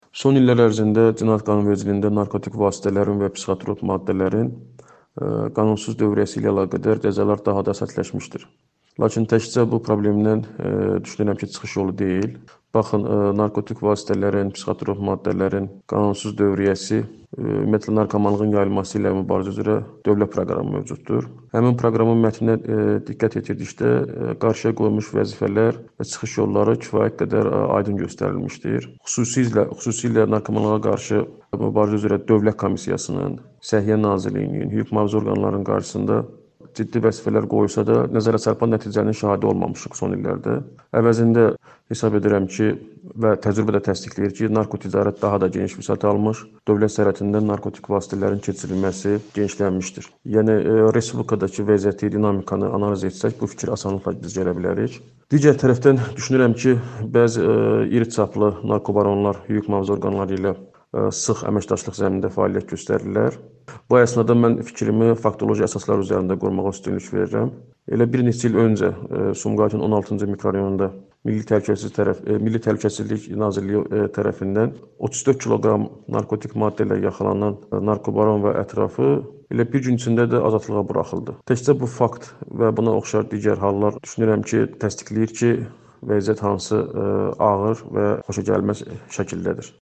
keçmiş müstəntiq